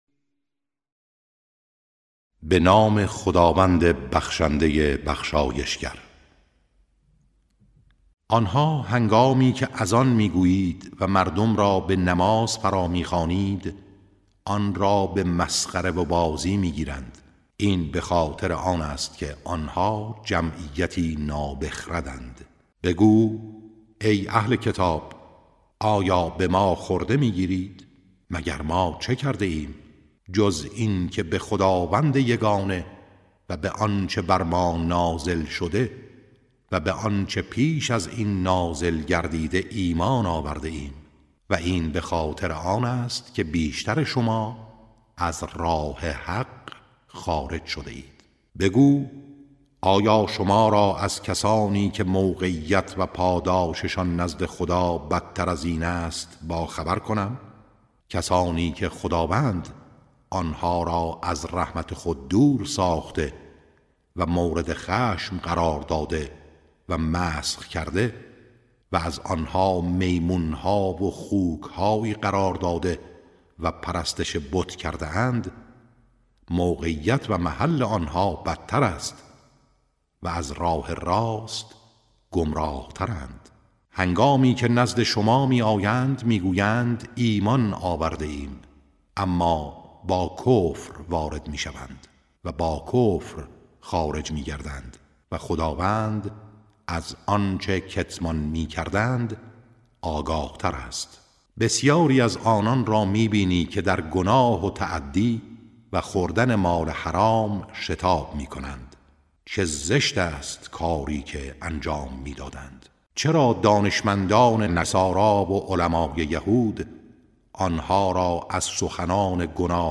ترتیل صفحه ۱۱۸ سوره مبارکه مائده(جزء ششم)